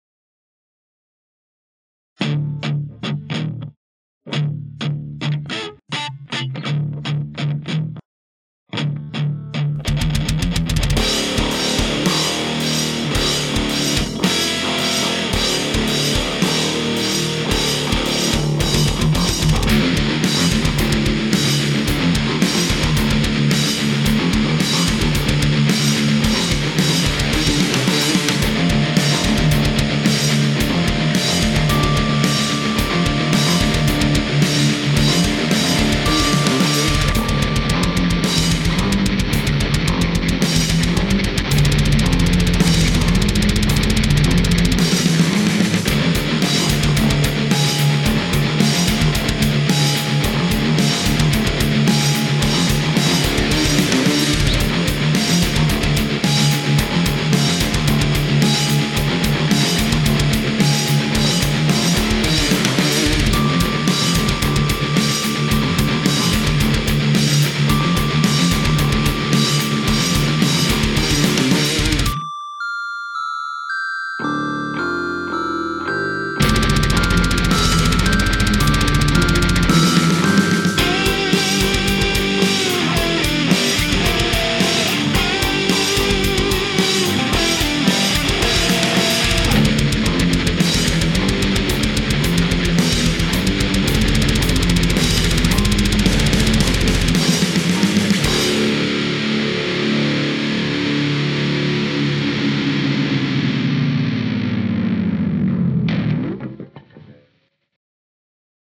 Трек в стиле metall
Писал и записывал все партии сам: 1)Ударные - Addictive Drums 2)Гитара - Amp Orange Dark Terror\Marshall 1*12 80W 3)Микрофон - Shure sm57 в центре динамика 4)Бас - через процессор напрямую: Line 6 pod xt live 5) Вокал - позже будет записан 6)Акустическое оформление помещения - отсутствует, к сожалению. Писал в квартире.